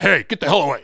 1 channel
welder-donttouch4.mp3